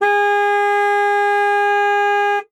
Car Horn